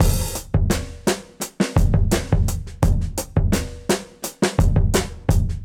Index of /musicradar/dusty-funk-samples/Beats/85bpm
DF_BeatA_85-01.wav